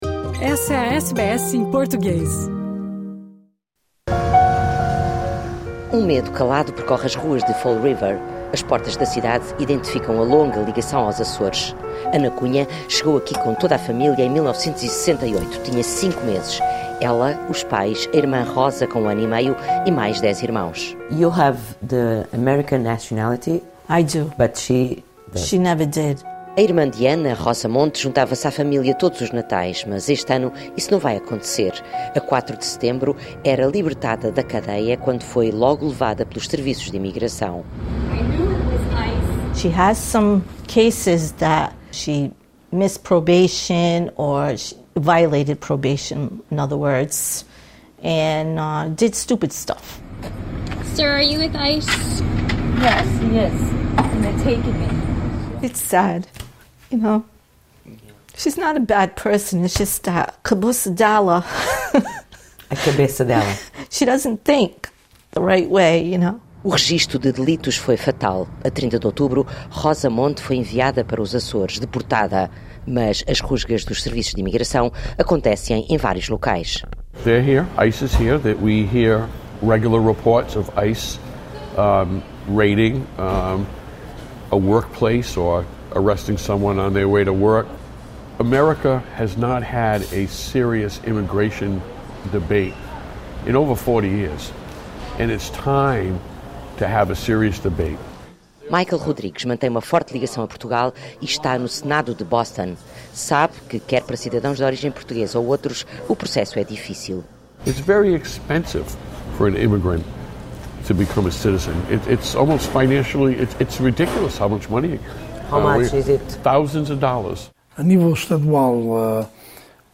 Nos Estados Unidos, as rusgas dos agentes de imigração (ICE) provocam medo nas comunidades portuguesas de New Bedford e Fall River. No estado do Massachusetts já foram detidas 1.400 pessoas de várias nacionalidades. A reportagem é dos correspondentes da RTP nos Estados Unidos